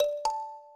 kalimba_da.ogg